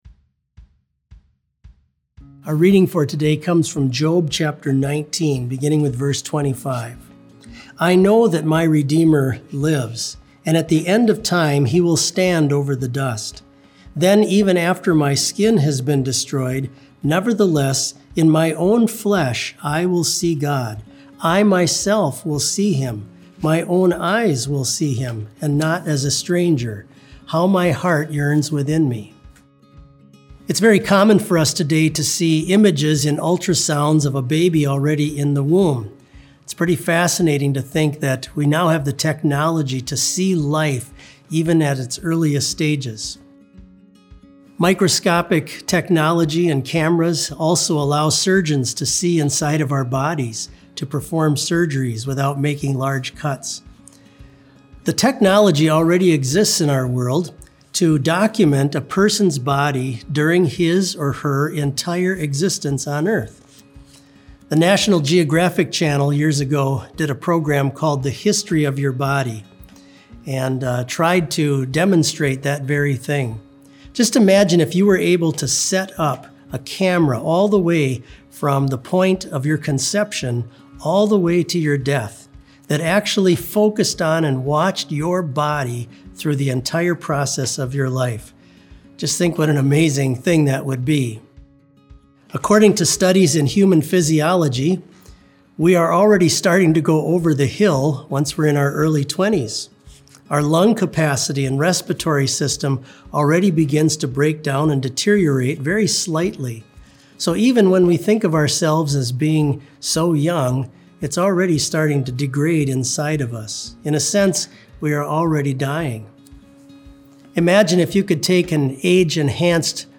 Complete service audio for BLC Devotion - April 15, 2020